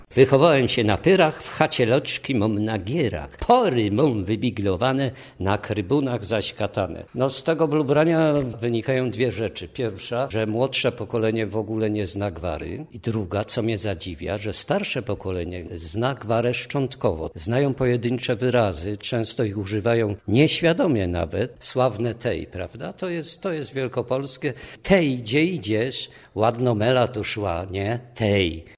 8lafw9uh4z7ywb0_gwara.mp3